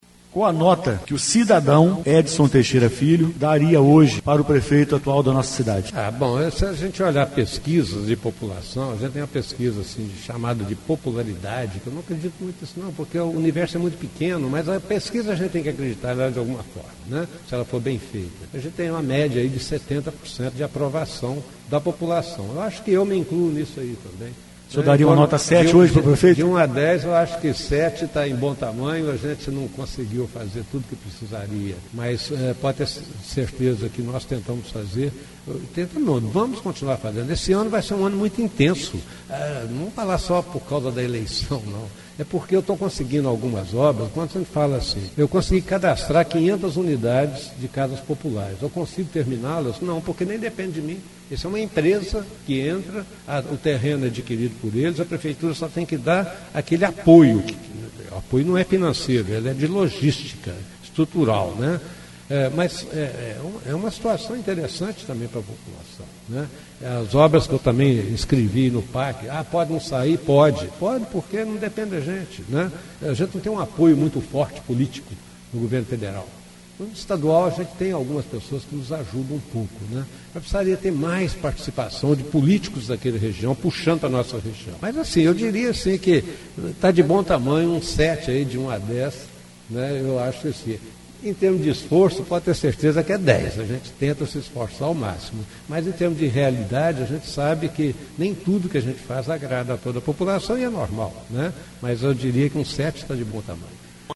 Trecho da entrevista exibida na Rádio Educadora AM/FM